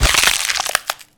break3.wav